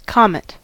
comet: Wikimedia Commons US English Pronunciations
En-us-comet.WAV